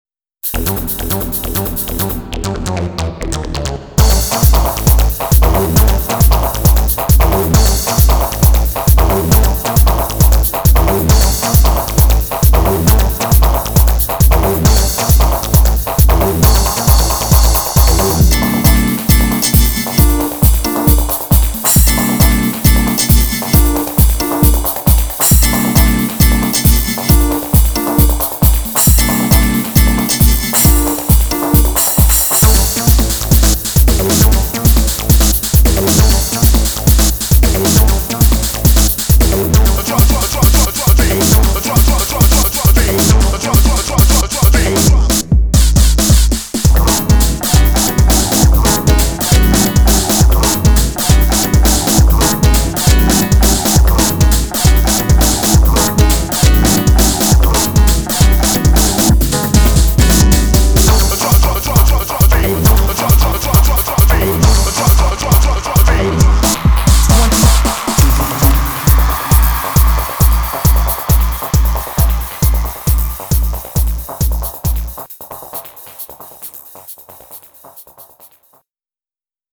BPM135
Audio QualityPerfect (High Quality)
Genre: TECHNO.